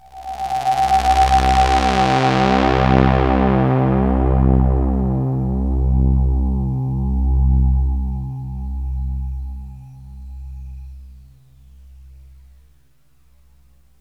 AMBIENT ATMOSPHERES-2 0005.wav